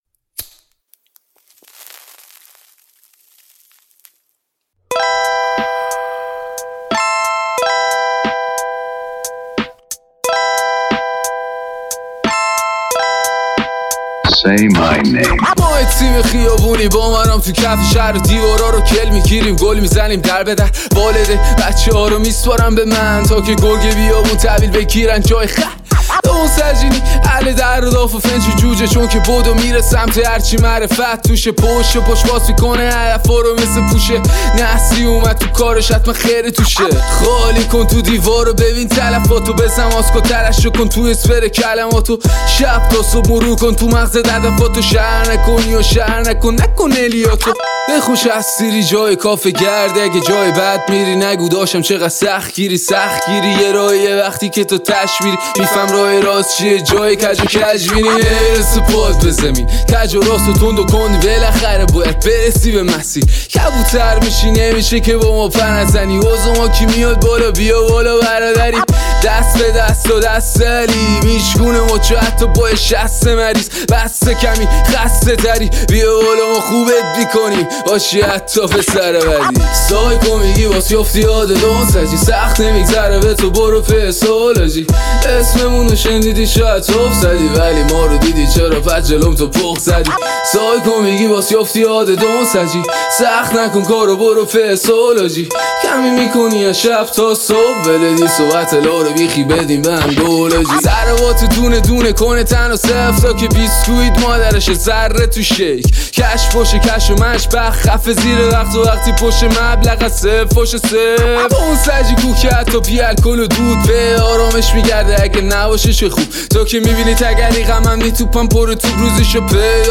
آهنگ رپ